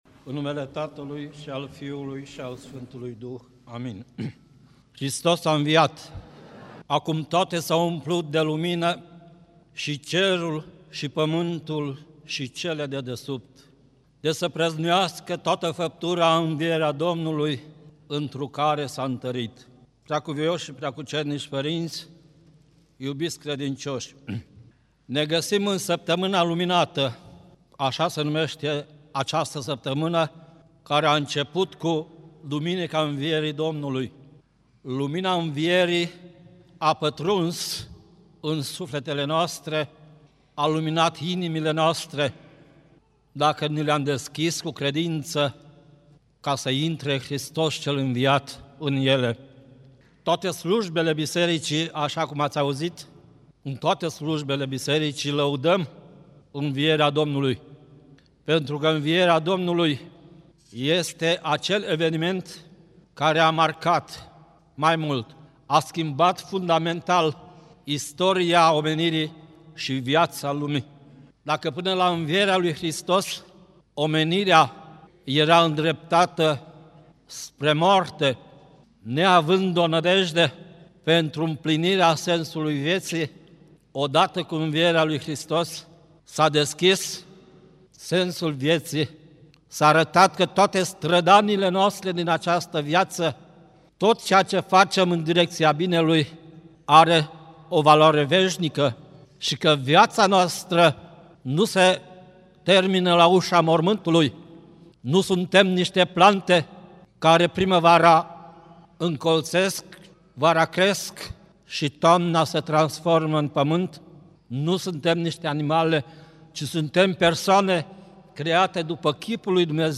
Predică la sărbătoarea Izvorului Tămăduirii
Cuvinte de învățătură Predică la sărbătoarea Izvorului Tămăduirii